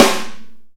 normal-hitclap.ogg